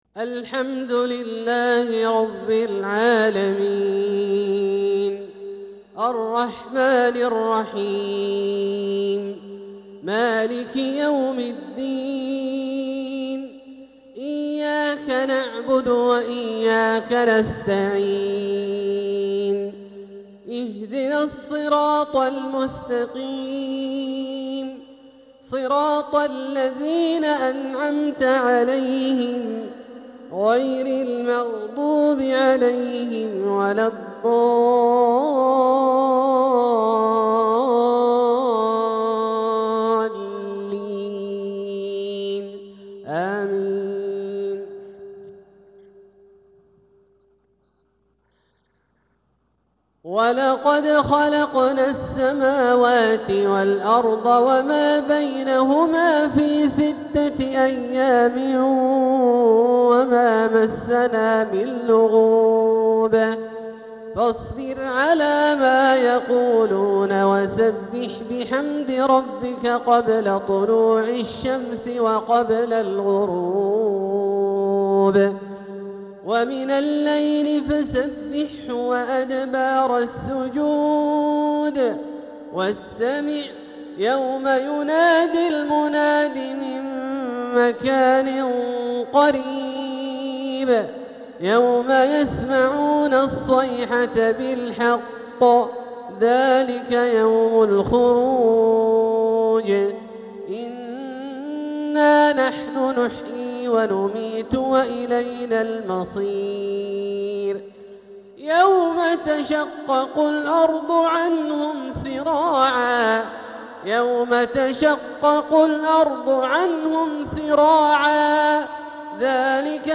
تلاوة لخواتيم سورتي ق و القلم | مغرب الأربعاء 12 صفر 1447هـ > ١٤٤٧هـ > الفروض - تلاوات عبدالله الجهني